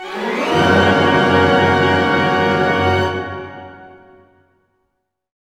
Index of /90_sSampleCDs/Roland - String Master Series/ORC_Orch Gliss/ORC_Major Gliss